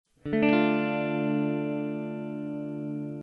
D minor (root note is D, flattened third note of scale is F).